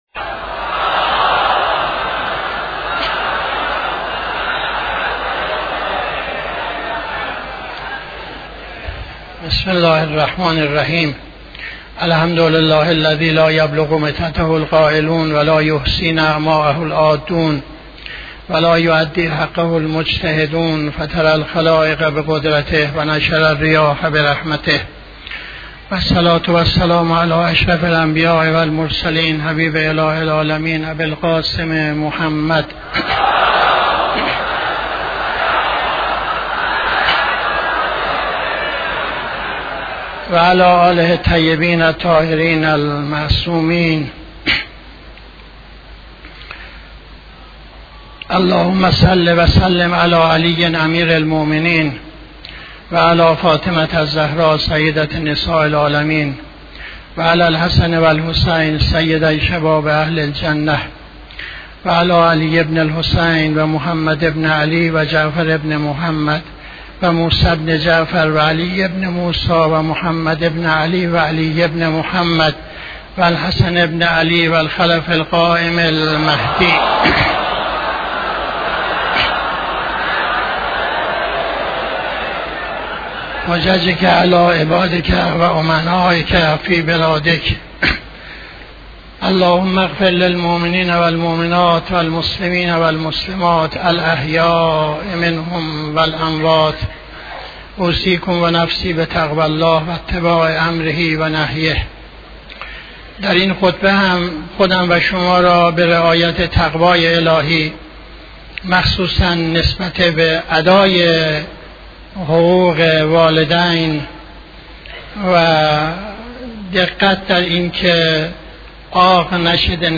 خطبه دوم نماز جمعه 26-12-79